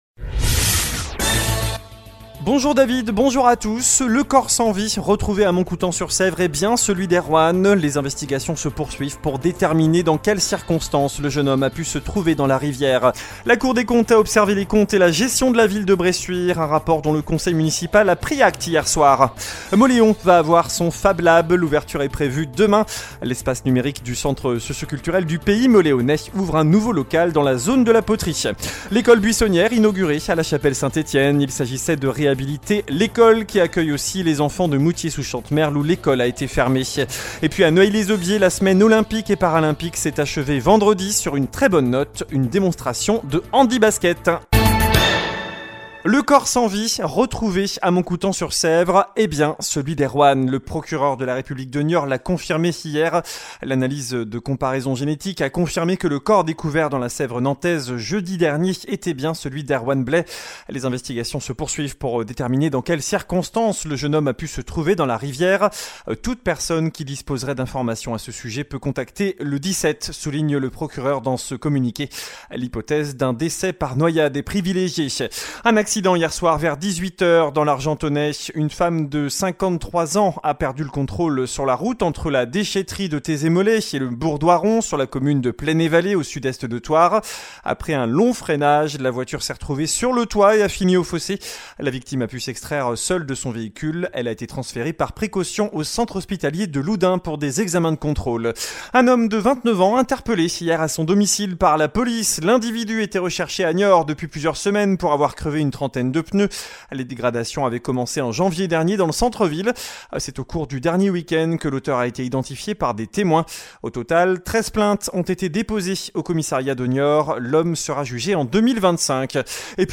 Journal du mardi 9 avril (midi)